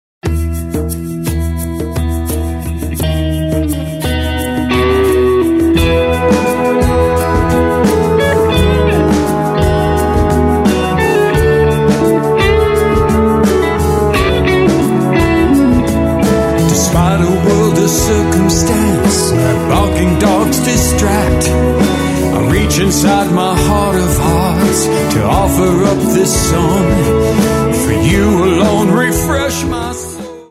French Horn
Flute